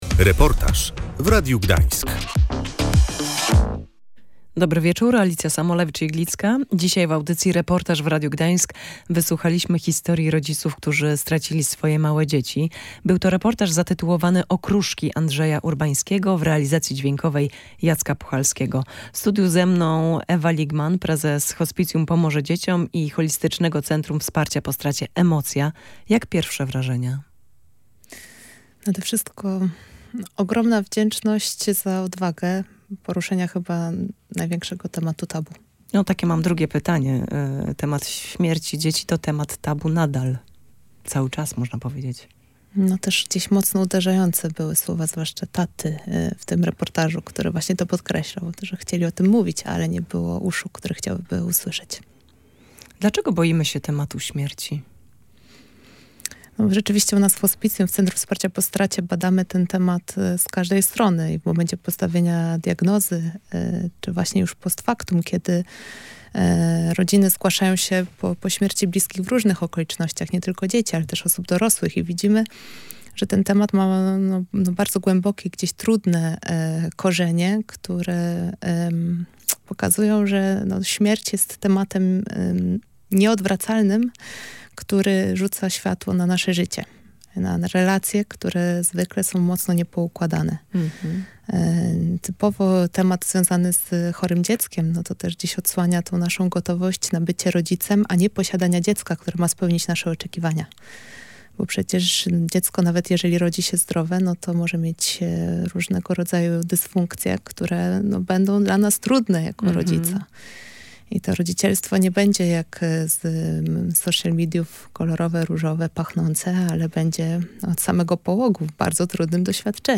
Tylko razem można pokonać ból po stracie dziecka. Posłuchaj reportażu „Okruszki”
Reportaż „Okruszki” nawiązuje do kilkudziesięciu prawdziwych i do bólu szczerych historii rodziców, którzy stracili swoje maleńkie dzieci.
Rodziców, którzy zdecydowali się o tym opowiedzieć, połączyło przekonanie, że choć nie widzą sensu w śmierci dzieci, widzą niezaprzeczalny sens ich życia – bez względu na to, jak długo trwało.